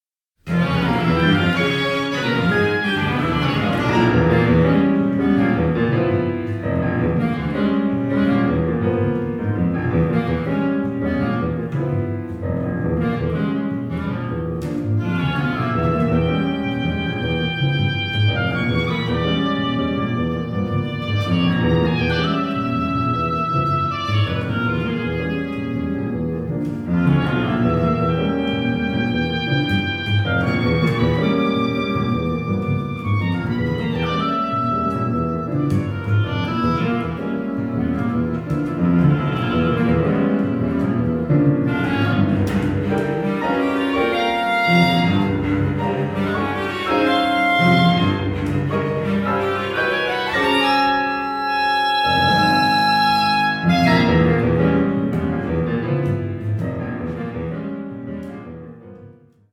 oboe
clarinet
cello
piano